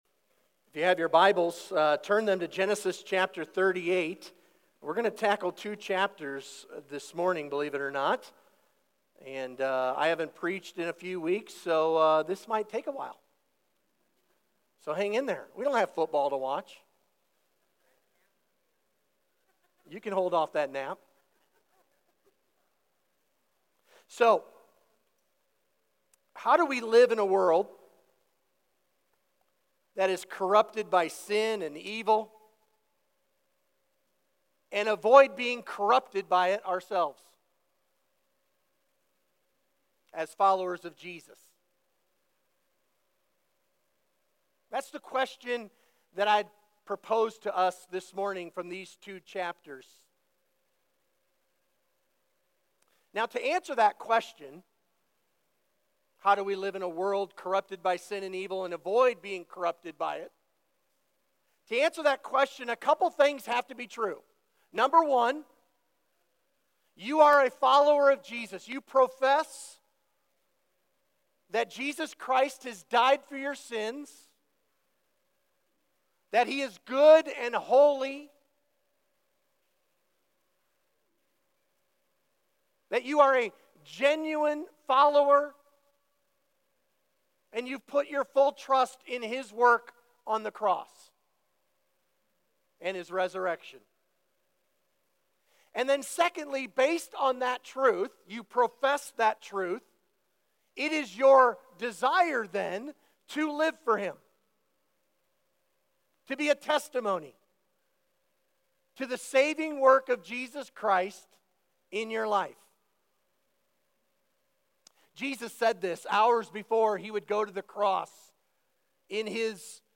Sermon Questions: Part 1: A Compromised Life (Judah) Read: Genesis 38:1–2, 12 Point 1: Judah went willingly into a worldly environment.